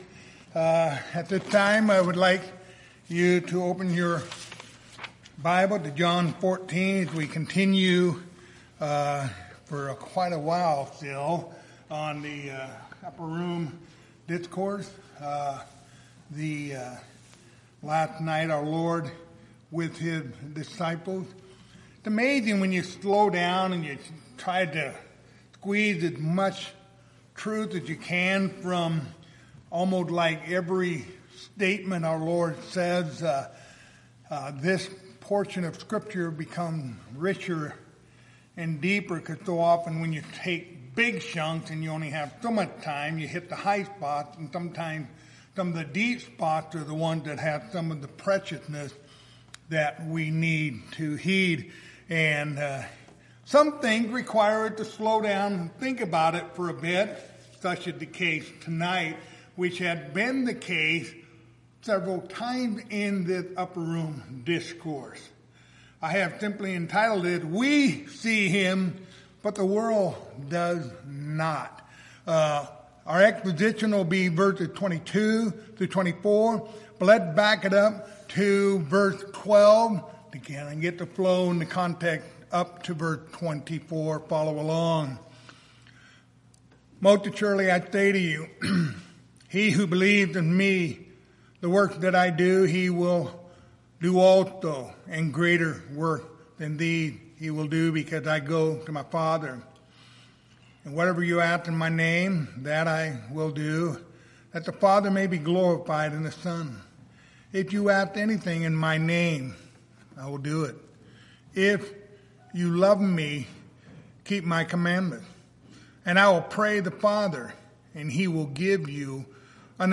Passage: John 14:22-24 Service Type: Wednesday Evening